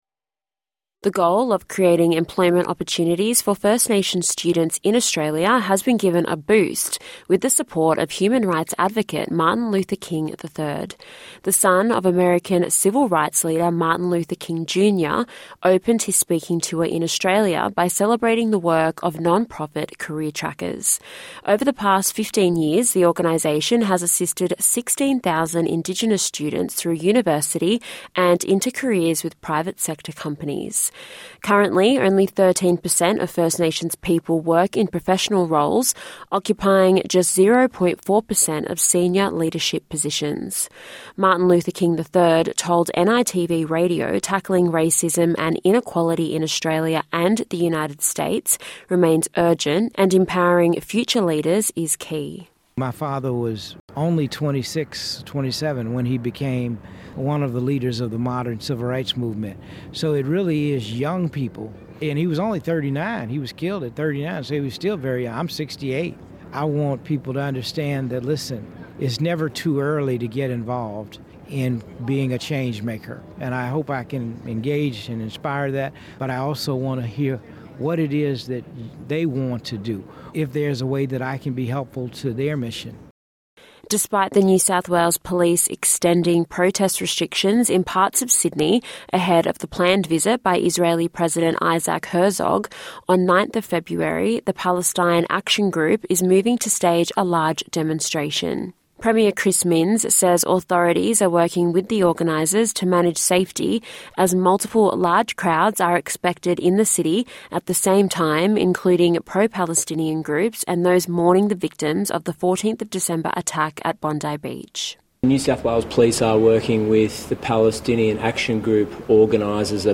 The latest National and International news for the 4th February 2026.